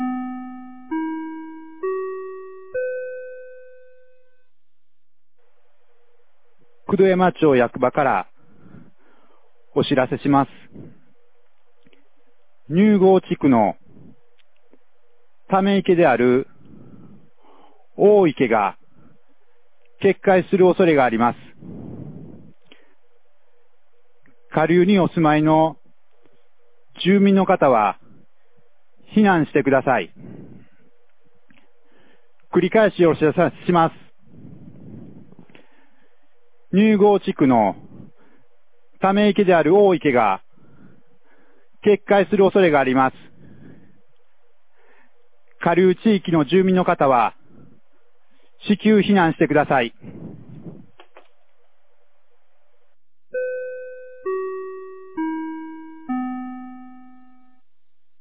2023年06月02日 14時58分に、九度山町より入郷地区へ放送がありました。